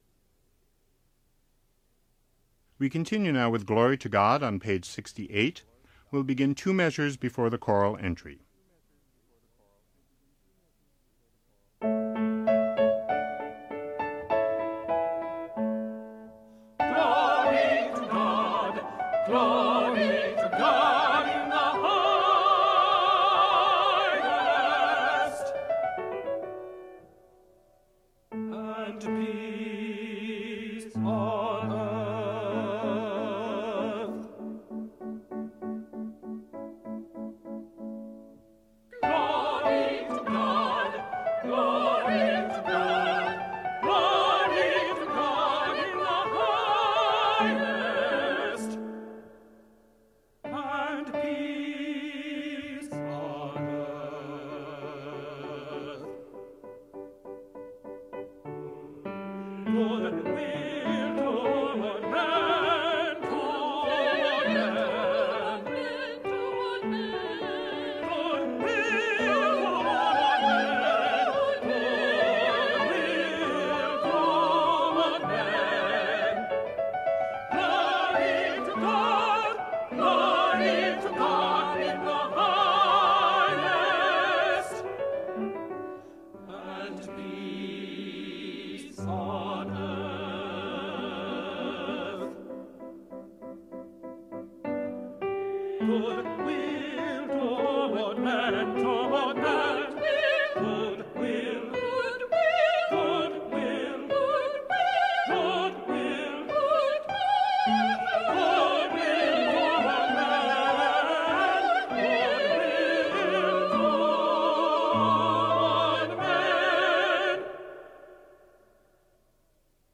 They are divided into two sections with either Alto and Bass together  or  Soprano and Tenor – since in each case the parts are well separated and tonally different you should be able to hear your part fairly clearly.
Soprano/Tenor